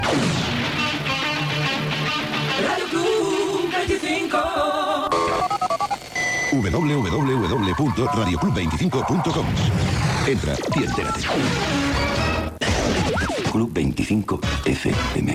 Identificació de l'emissora i adreça web